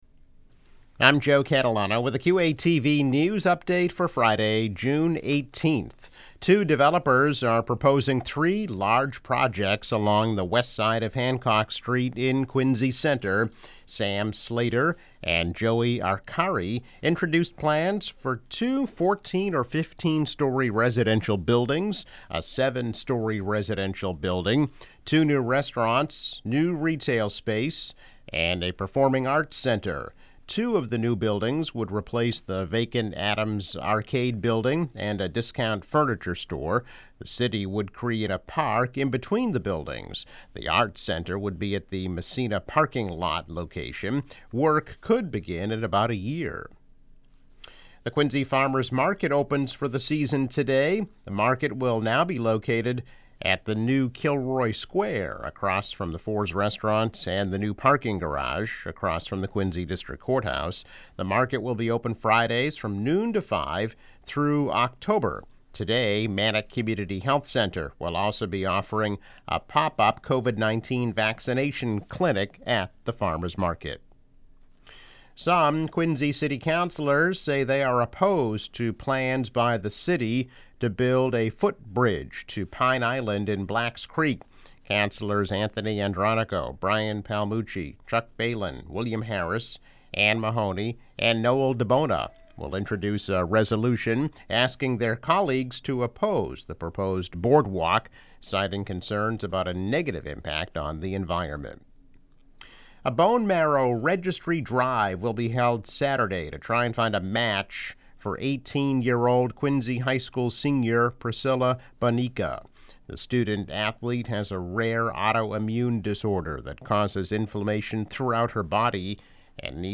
News Update - June 18, 2021